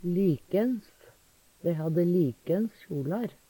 likens - Numedalsmål (en-US)